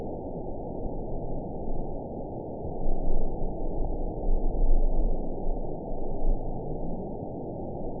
event 920145 date 02/29/24 time 22:34:01 GMT (1 year, 9 months ago) score 8.76 location TSS-AB10 detected by nrw target species NRW annotations +NRW Spectrogram: Frequency (kHz) vs. Time (s) audio not available .wav